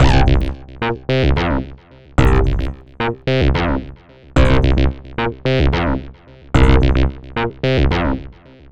UR 303 acid bass 1 a.wav